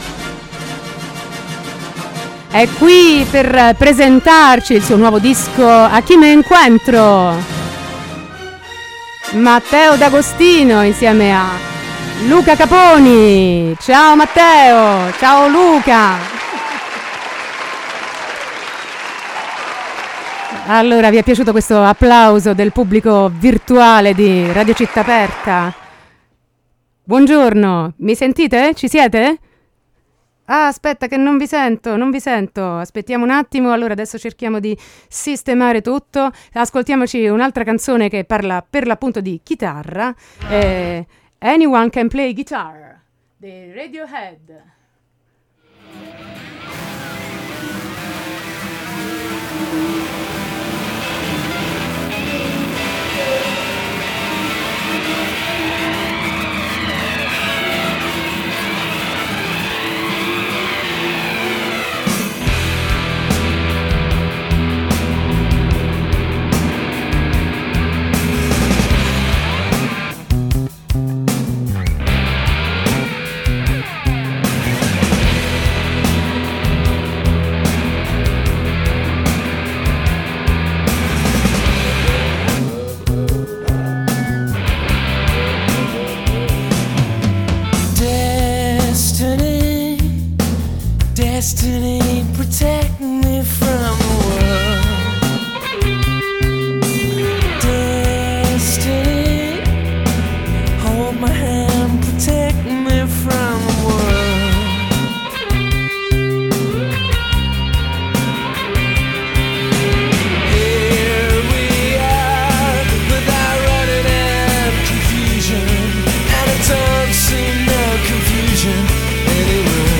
Intervista e minilive
Il chitarrista flamenco ha presentato il suo nuovo disco e ha suonato alcuni brani